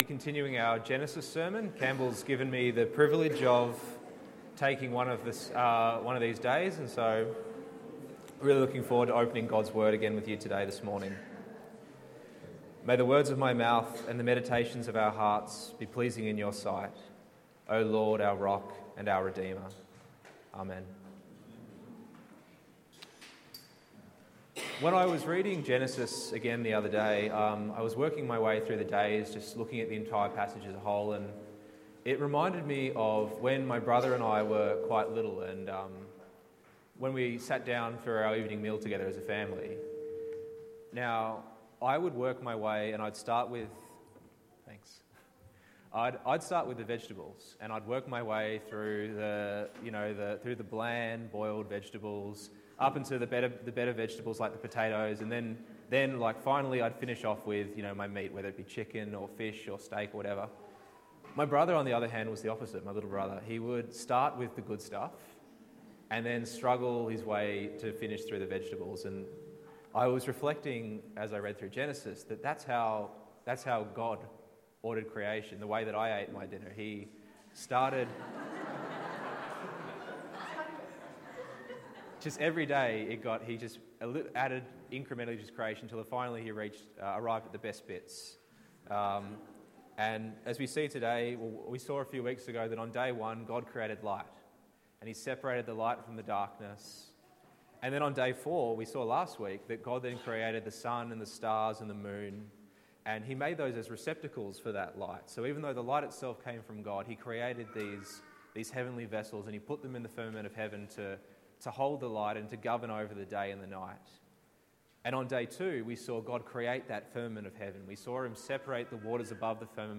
Today at church we examine Day Five of Creation, where God creates living souls, and fills the seas and the firmament of Heaven with swarms of teeming, abundant life.
Genesis 1:20-23 Sermon